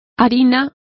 Complete with pronunciation of the translation of meal.